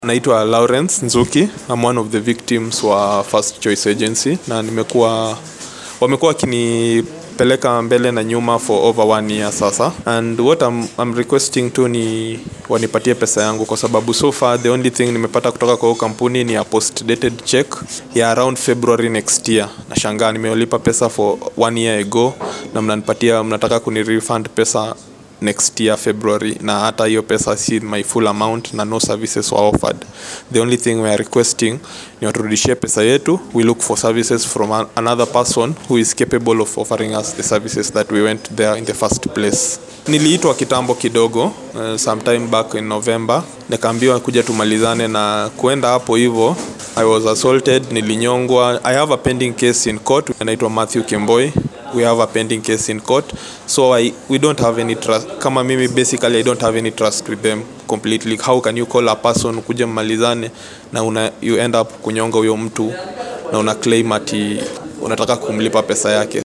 SOUND-BITE-FIRSTCHOICE-VICTIM-.mp3